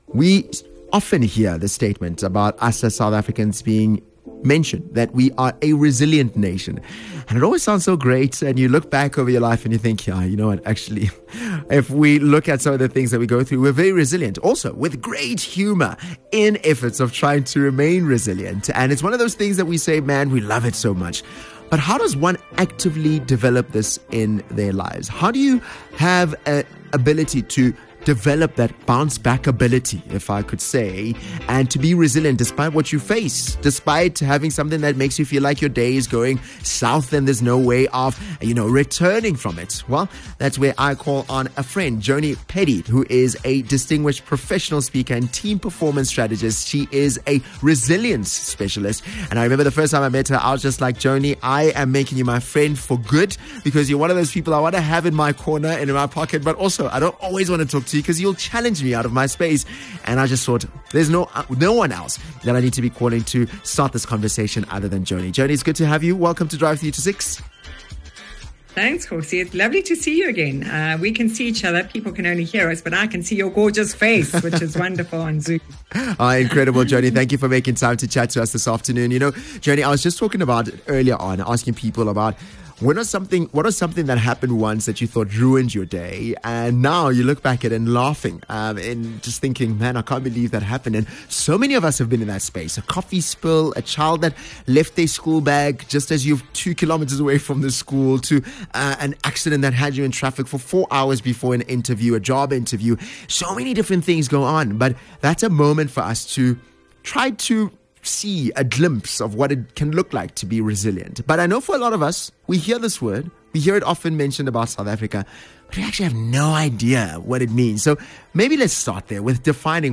This is part 1 of a 2-part conversation you won’t want to miss.